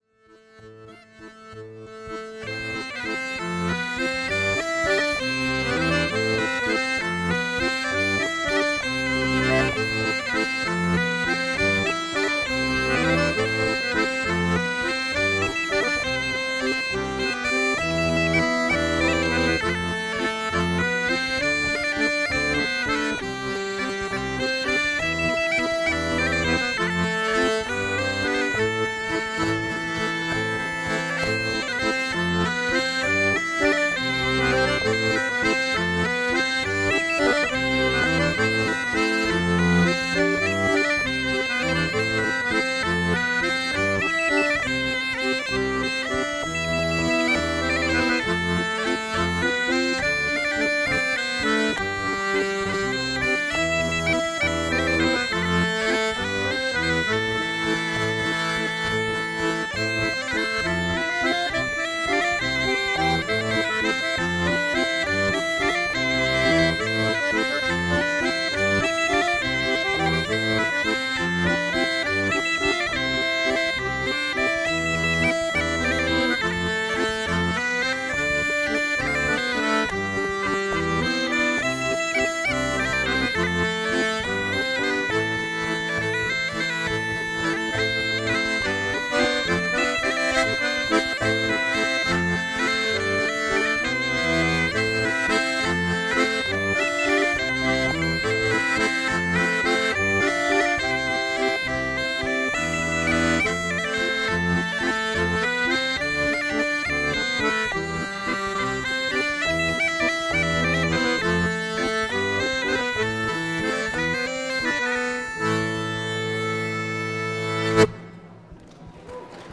(RECORDED  ENREGISTREMENT LIVE):
Walzer